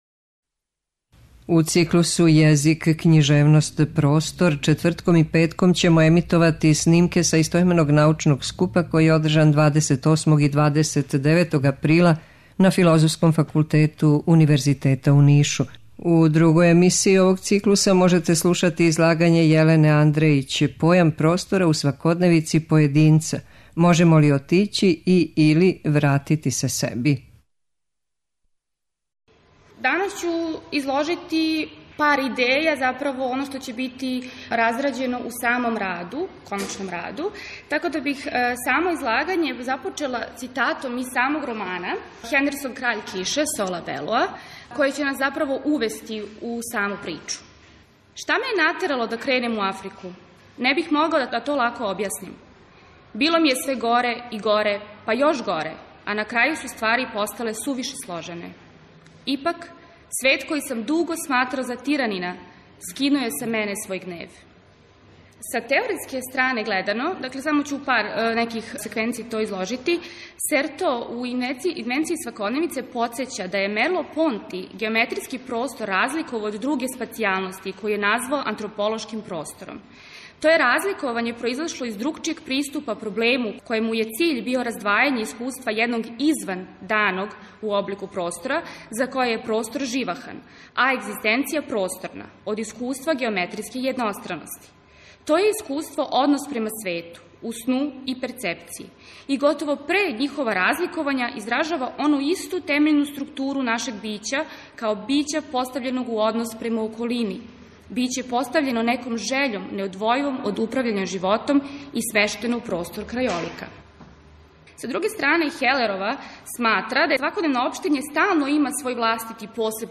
У циклусу ЈЕЗИК, КЊИЖЕВНОСТ, ПРОСТОР четвртком и петком ћемо емитовати снимке са истoименог научног скупа, који је ордржан 28. и 29. априла на Филозофском факултету Универзитета у Нишу.
Научни скупoви